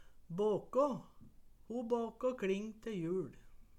båkå - Numedalsmål (en-US)